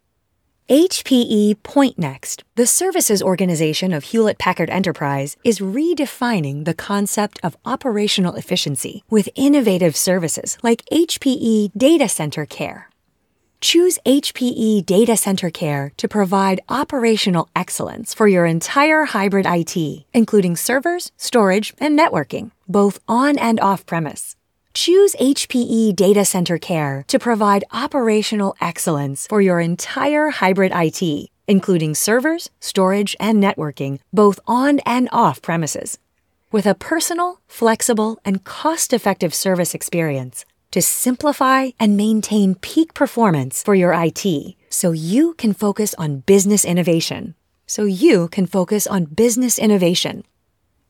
Seasoned voiceover actor with a broad range of skills
Tech Demo